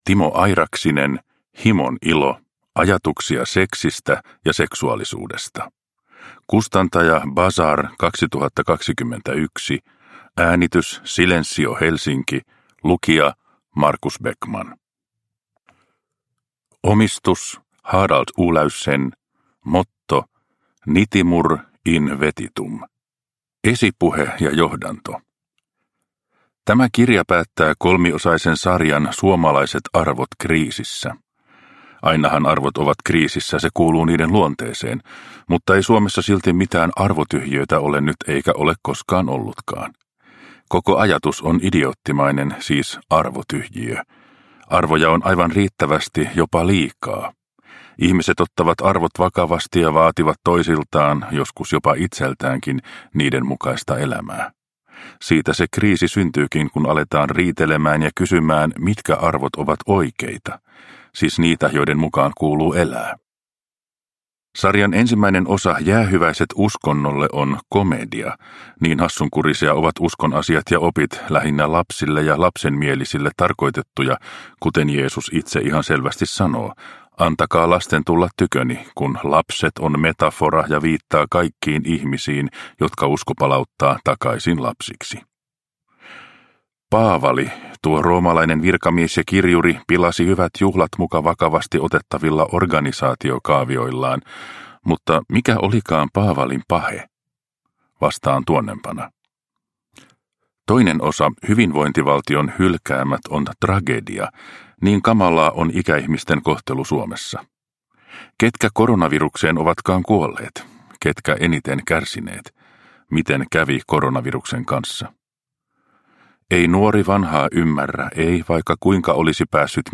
Himon ilo – Ljudbok – Laddas ner